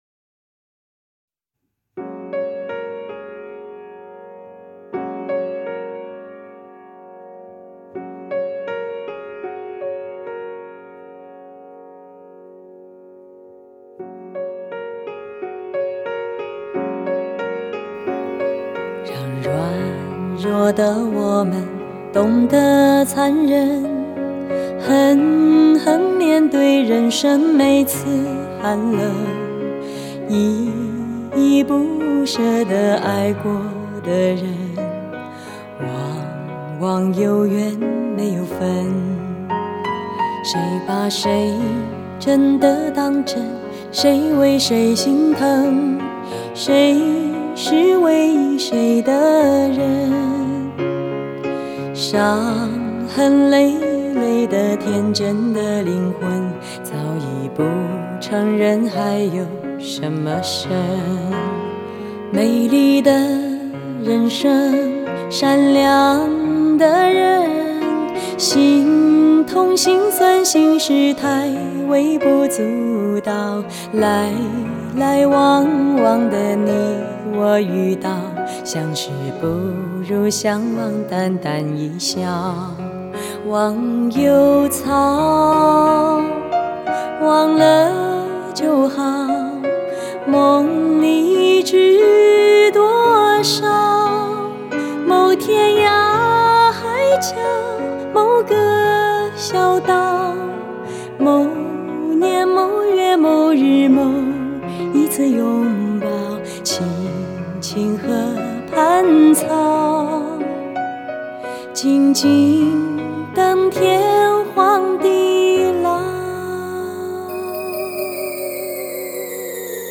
令你沉醉在音乐的海洋中......